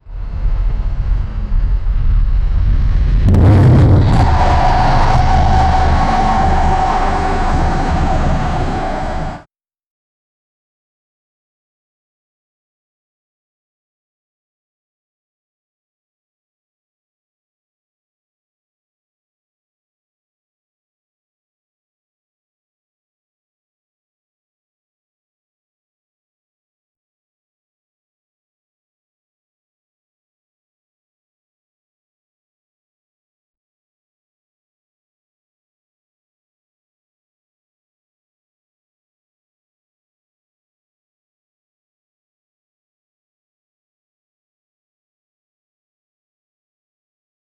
Lamborghini Sound Effects - Free AI Generator & Downloads
Lamborghini aventidor SVJ accelerating on a highway rip through traffic with loud EXHUAST poping and banging downshifting toa red light, then when green accelerating off again into a tunnel downshift loudly its exhaust poping big slames and accelerates through it
lamborghini-aventidor-svj-axnvgaiw.wav